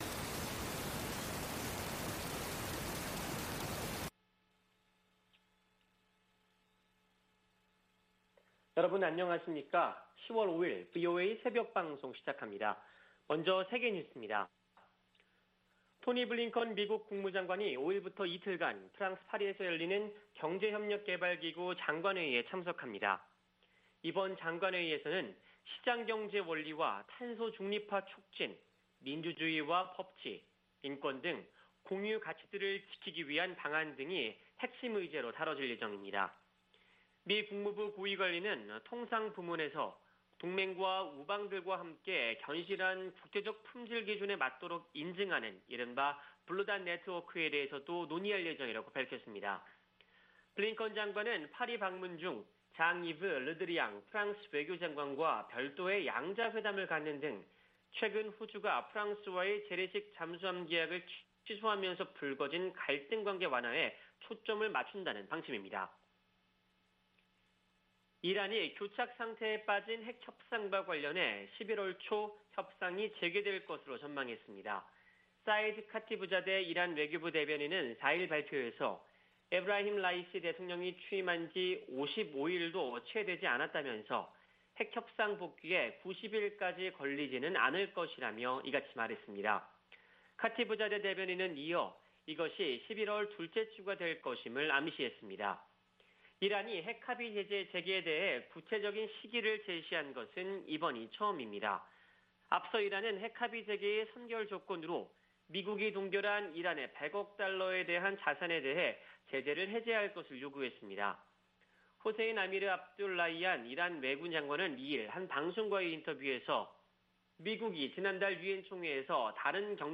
세계 뉴스와 함께 미국의 모든 것을 소개하는 '생방송 여기는 워싱턴입니다', 2021년 10월 5일 아침 방송입니다. '지구촌 오늘'에서는 기시다 후미오 신임 일본 총리 취임 소식 전해드리고, '아메리카 나우'에서는 임신 중절과 총기 문제 등 연방 대법원이 새 회기에 다루는 사건들 설명해드립니다.